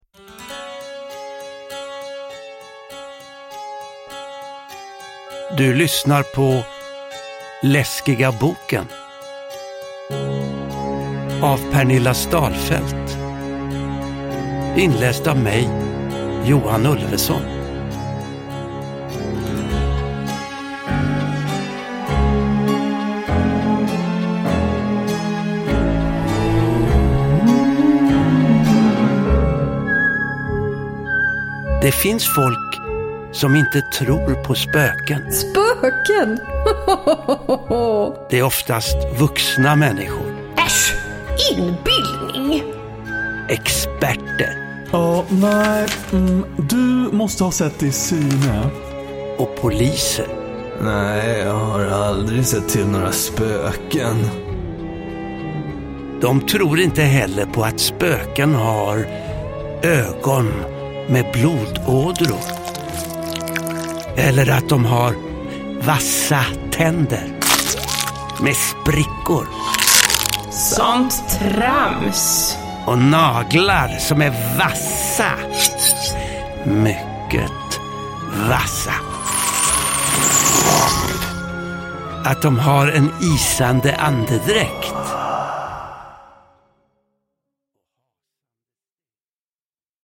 Läskiga boken – Ljudbok – Laddas ner